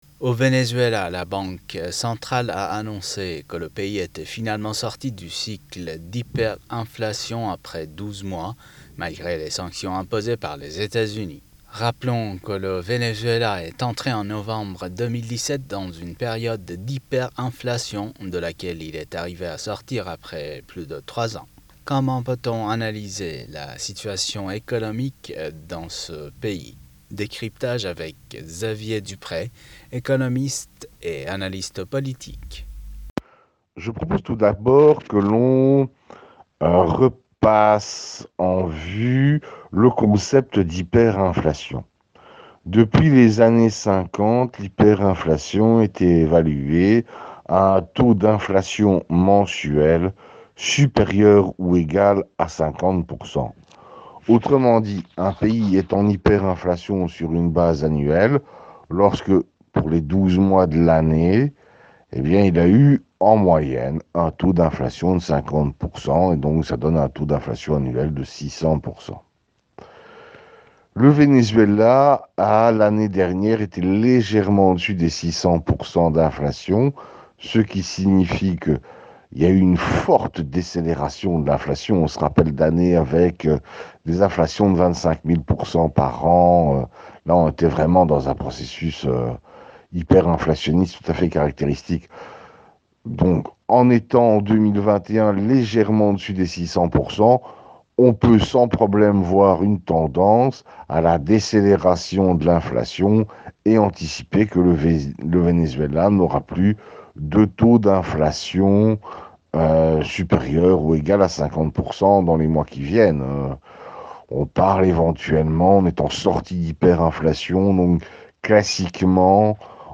économiste et analyste politique nous répond.